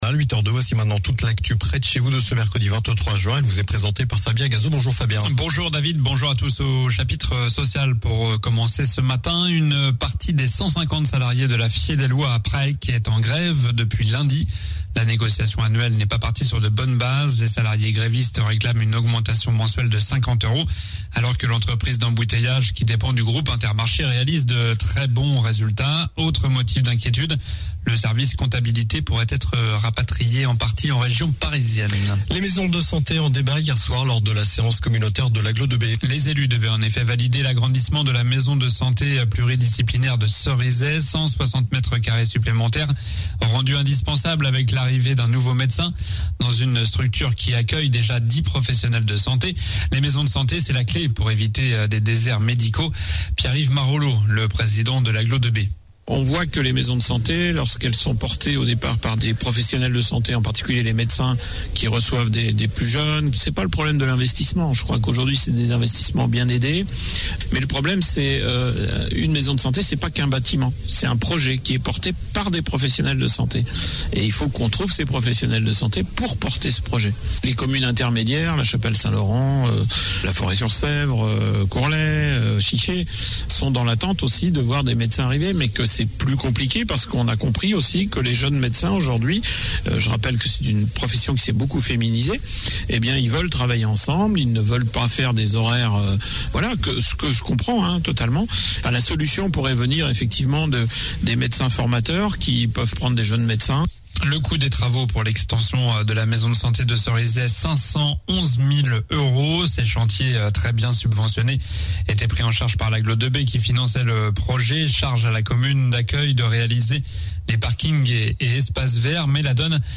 Journal du mercredi 23 juin (matin)